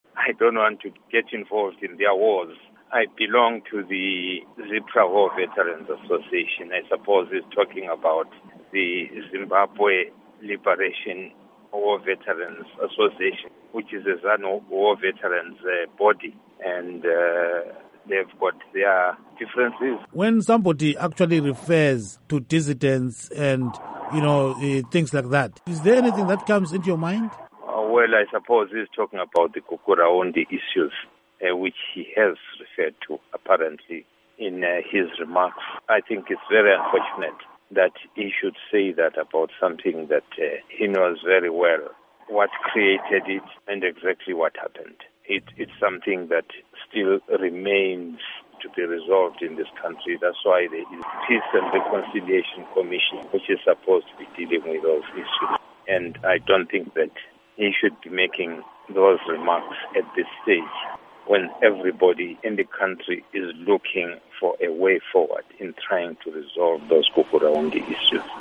Interview With Zapu Leader Dumiso Dabengwa on Gukurahundi Atrocities